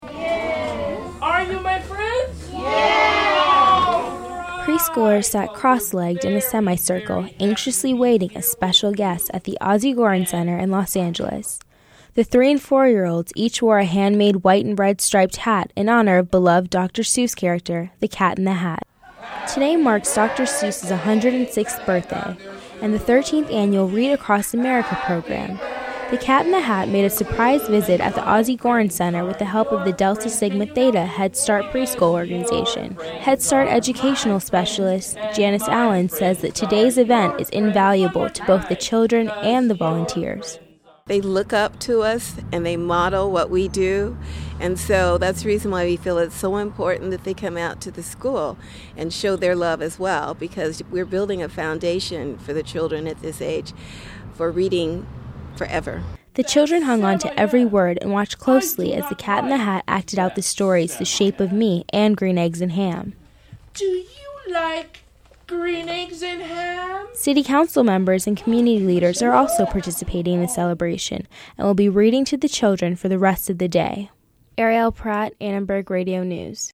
Preschoolers at the Ozzie Goren Center celebrated Dr. Seuss' 106th birthday and the 13th annual "Read Across America" program.